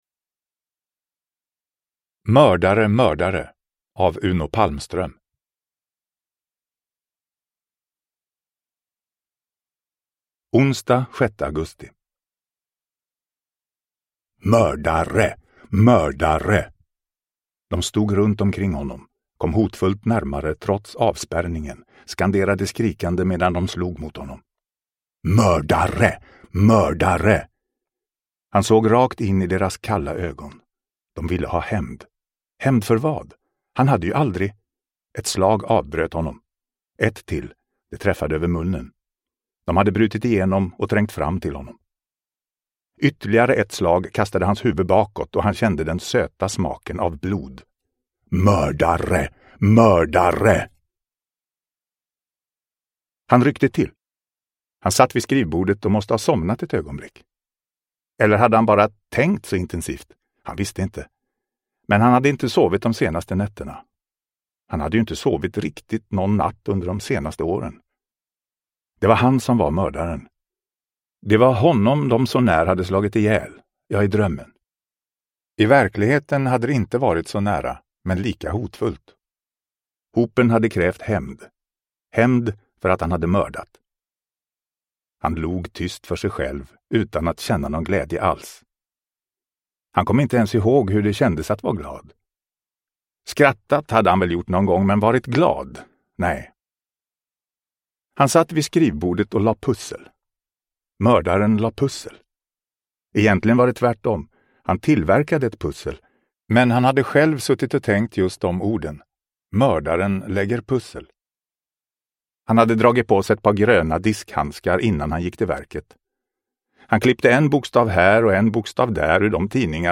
Mördare! Mördare! – Ljudbok – Laddas ner